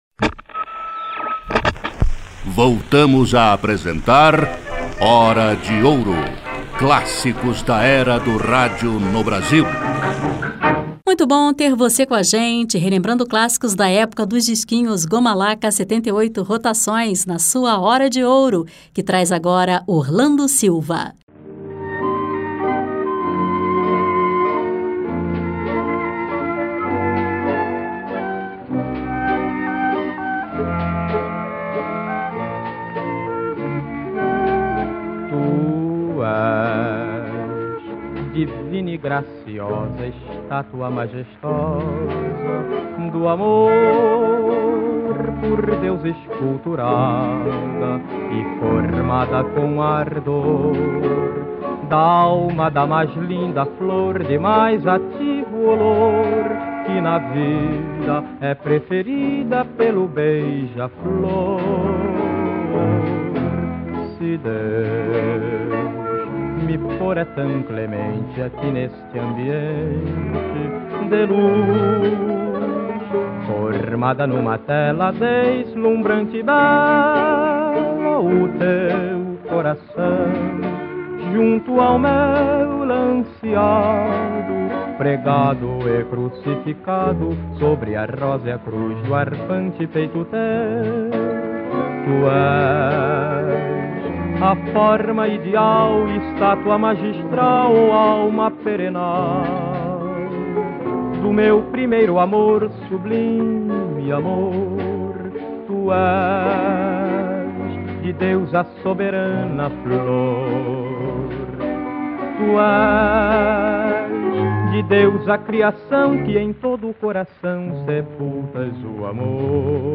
imperavam as vozes de grandes cantores e cantoras